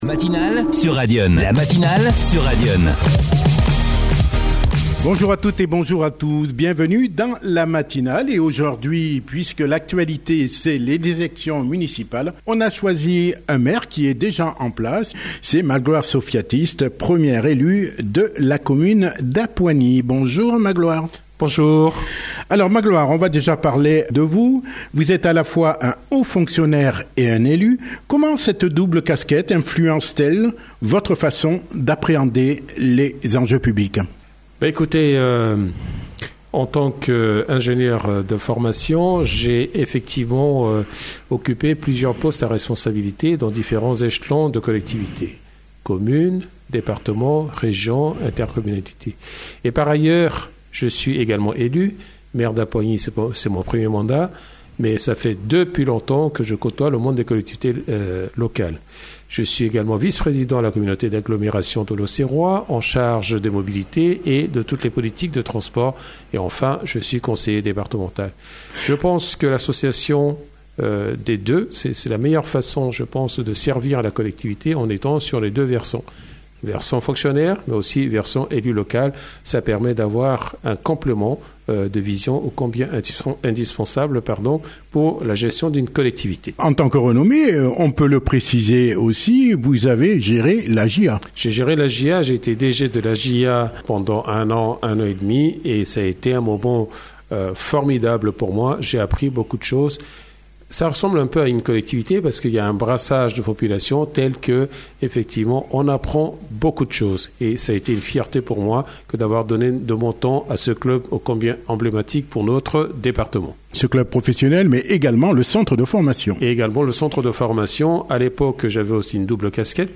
L’interview en Pocast